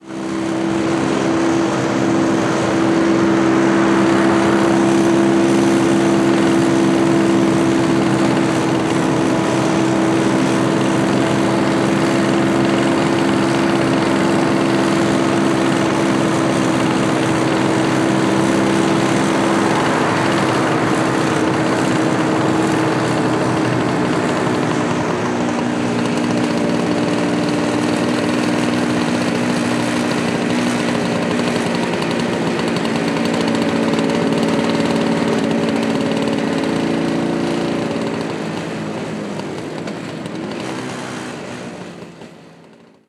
Moto marca Vespa motor continuo
motocicleta
Sonidos: Transportes